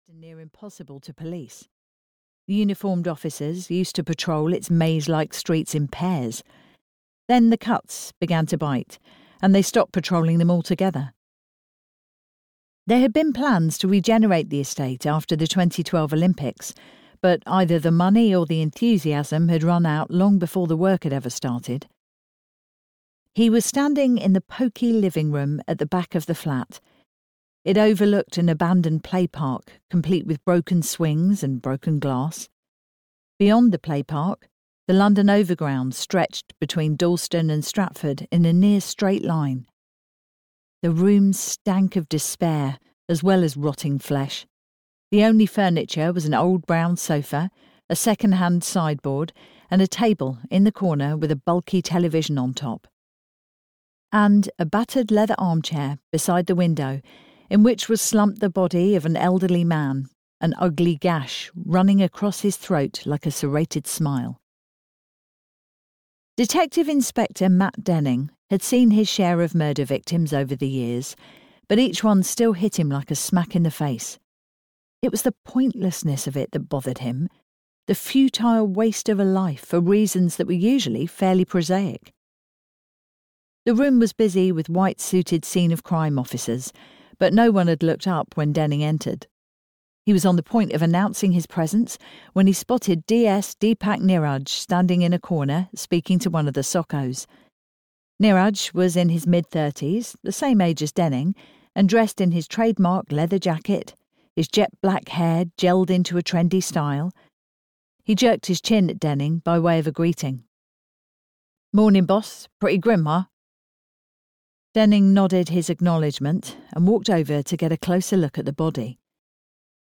The Darkness Within (EN) audiokniha
Ukázka z knihy